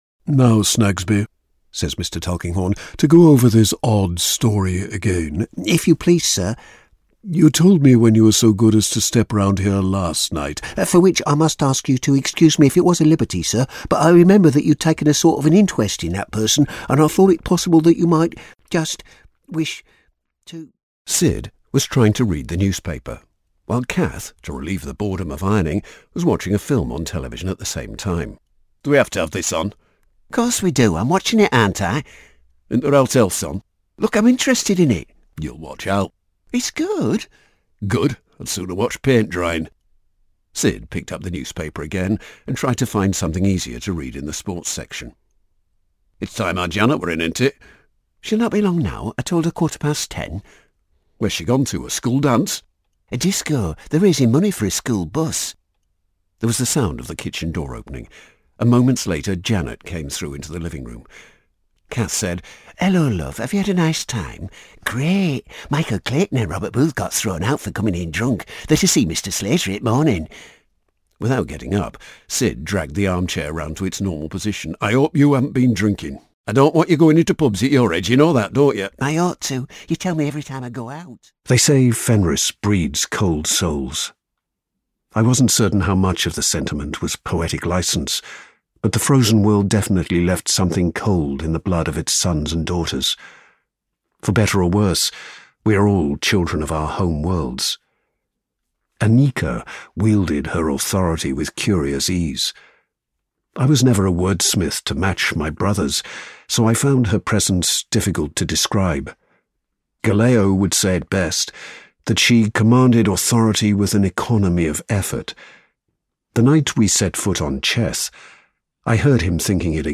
Englisch (Britisch)
Hörbücher
Erzählung
Meine Stimme wird normalerweise als warm, natürlich und unverwechselbar beschrieben und wird oft verwendet, um Werbe- und Erzählprojekten Klasse und Raffinesse zu verleihen.
Neumann U87-Mikrofon